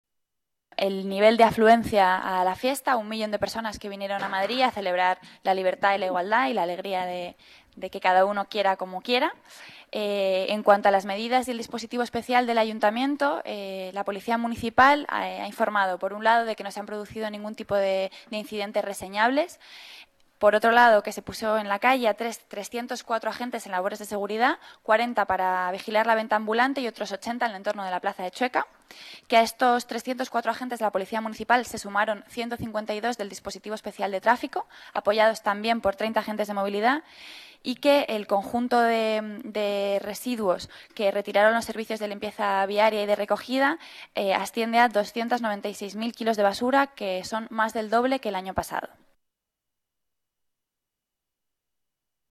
Nueva ventana:Declaraciones de Rita Maestre, portavoz del Gobierno municipal